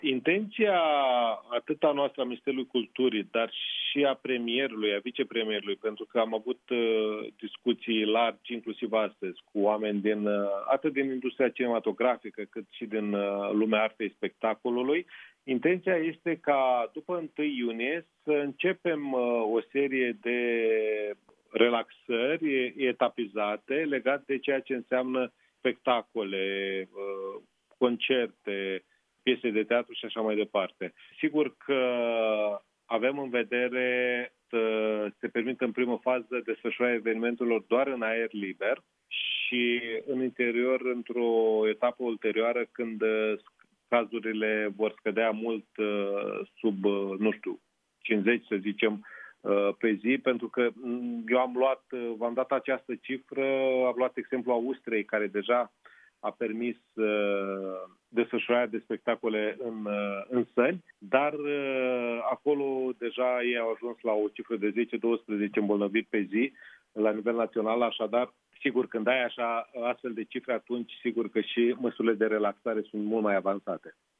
Cât privește actele artistice în spații închise, acestea s-ar putea relua abia după ce numărul noilor infecții zilnice cu SARS-CoV-2 va scădea sub pragul de 50, spune – la Europa FM – ministrul Culturii.